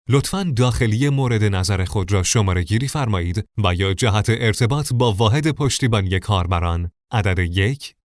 Narration
Male
Young
Adult
English (Local accent)